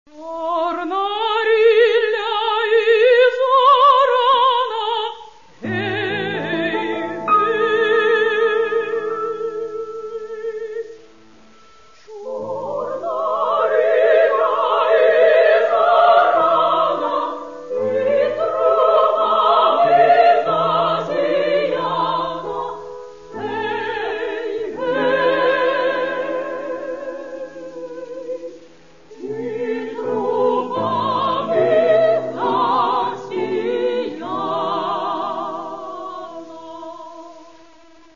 хоровые произведения, хоровые обработки народных песен и